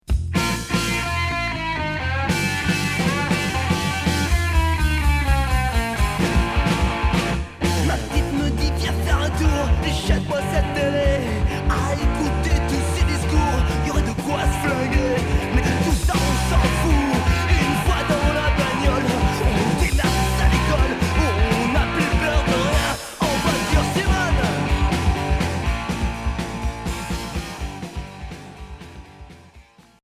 Punk rock Deuxième 45t retour à l'accueil